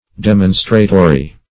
Search Result for " demonstratory" : The Collaborative International Dictionary of English v.0.48: Demonstratory \De*mon"stra*to*ry\, a. Tending to demonstrate; demonstrative.
demonstratory.mp3